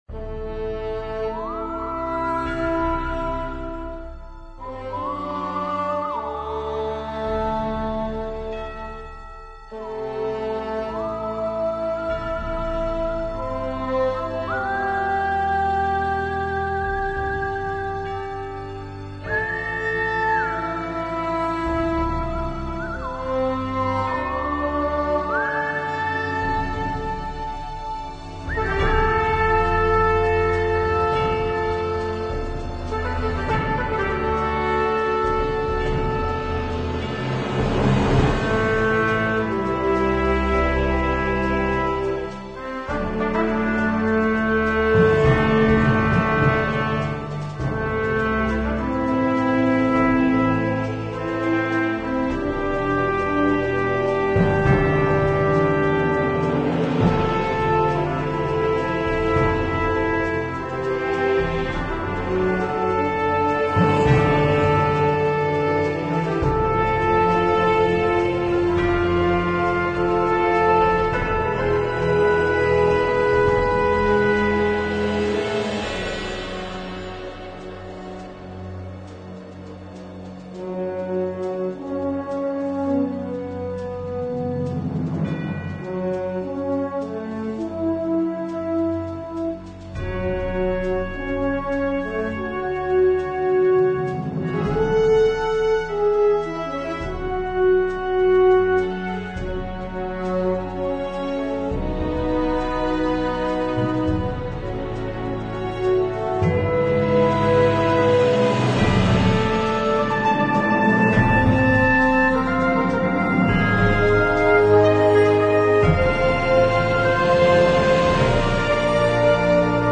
描述：带有西部英雄主义电影感觉的管弦乐曲。 特点是弦乐部分，完整的合唱，独奏巴松管和小号。